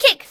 kick.ogg